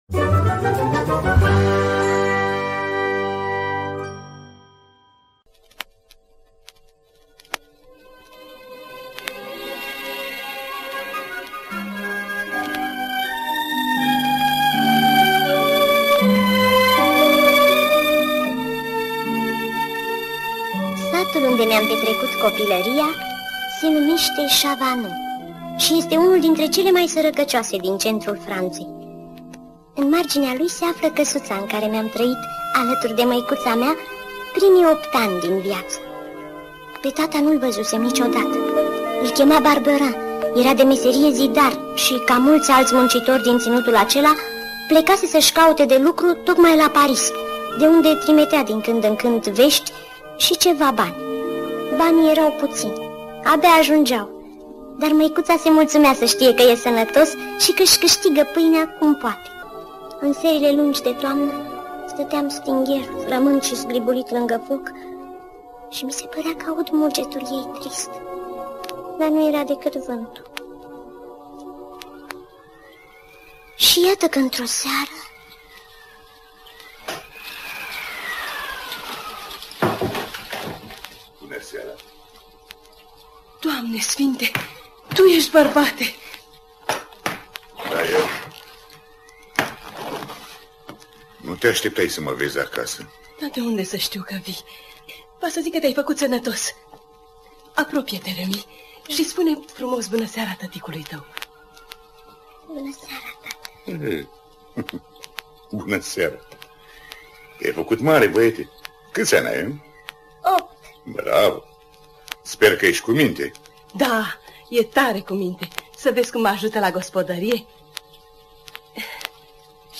Dramatizare